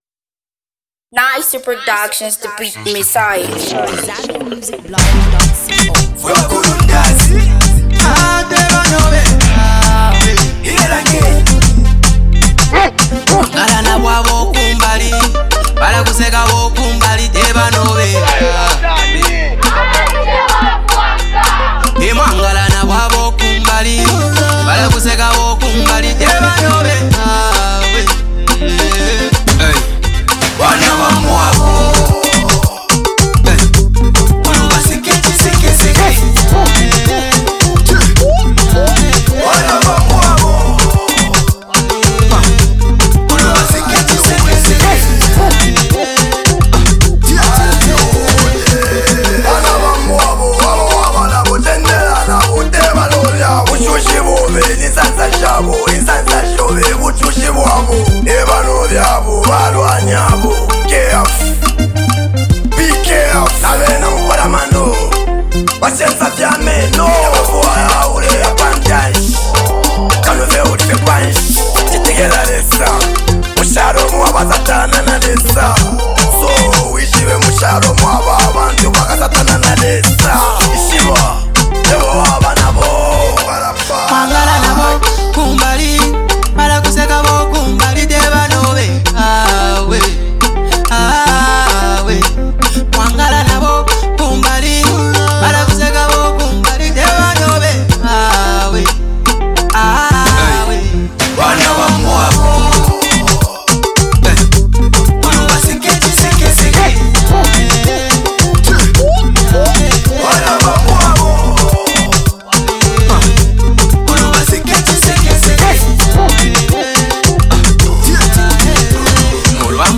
Copperbelt-based music duo
who also lends his vocals on the catchy hook